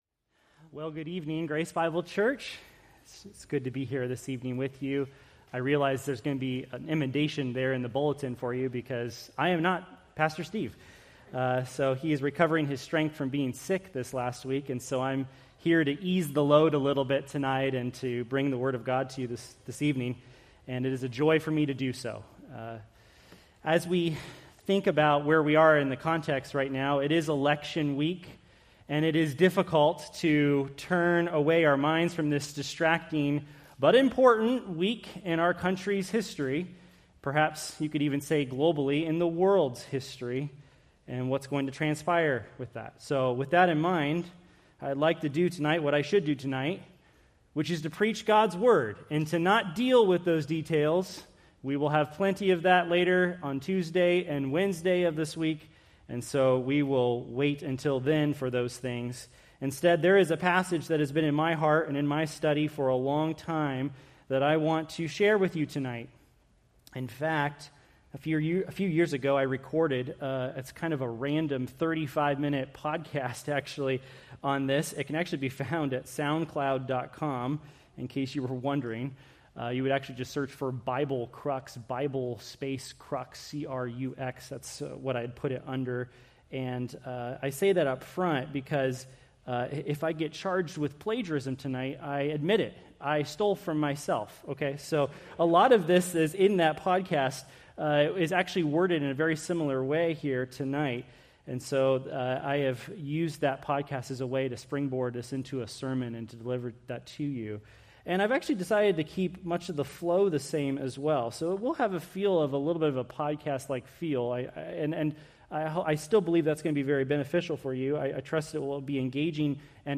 Preached November 3, 2024 from Romans 2:6-8